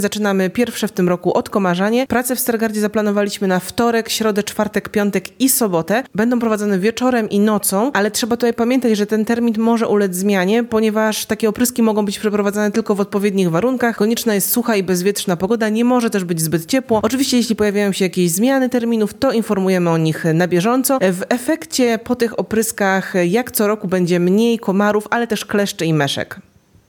mówi